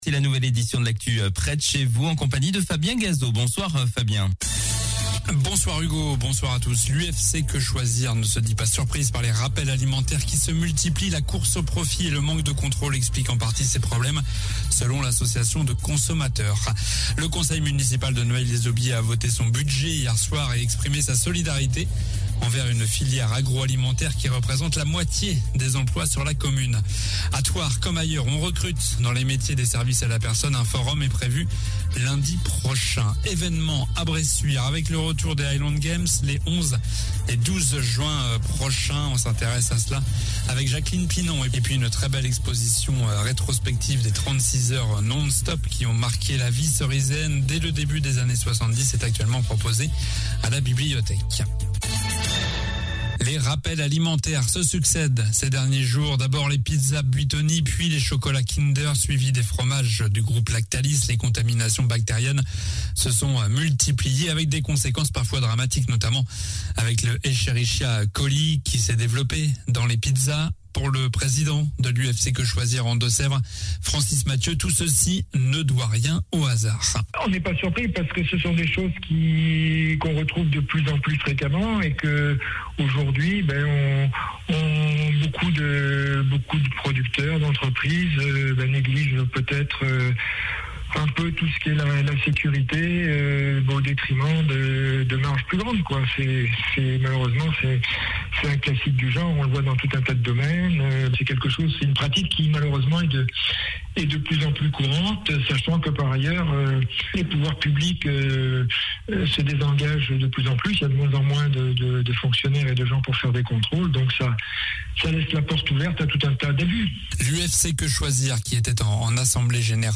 Journal du jeudi 7 avril (soir)